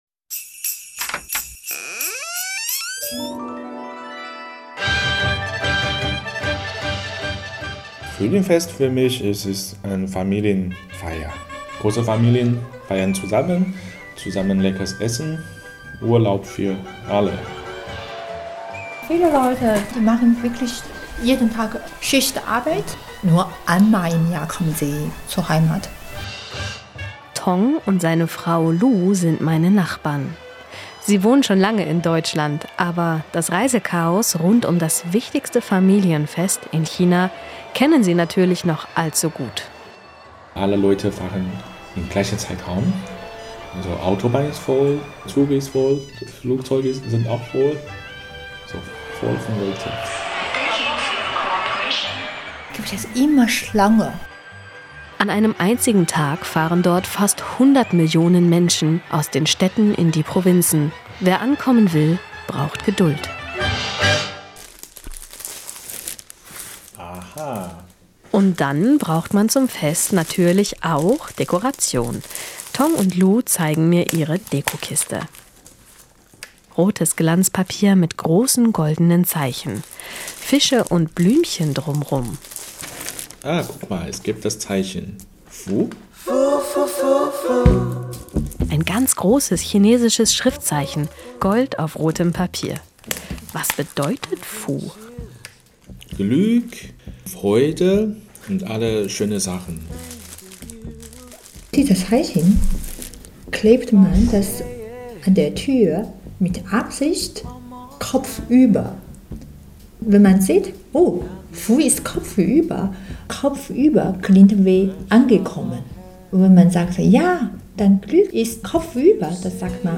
Inforadio Nachrichten, 10.12.2023, 10:00 Uhr - 10.12.2023